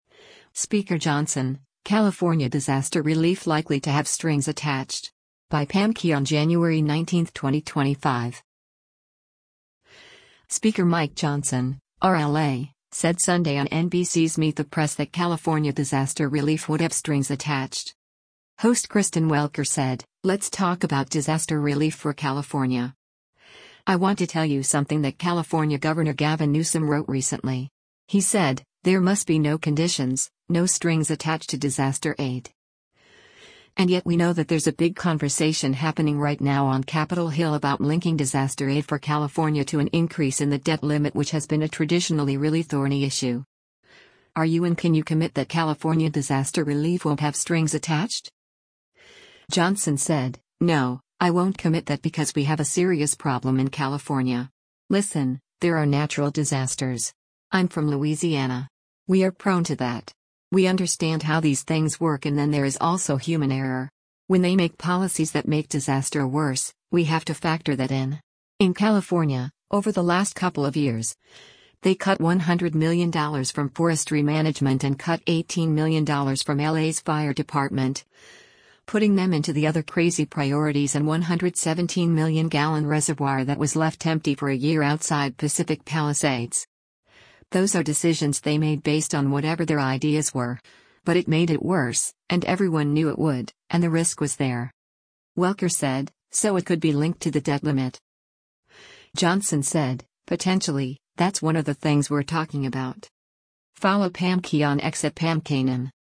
Speaker Mike Johnson (R-LA) said Sunday on NBC’s “Meet the Press” that California disaster relief would have strings attached.